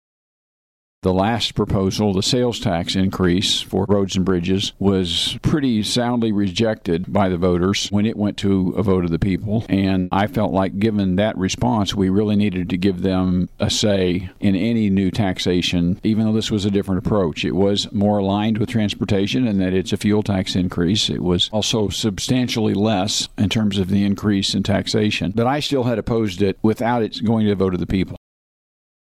The following cuts are taken from the above interview with Sen. Emery, for the week of March 28, 2016.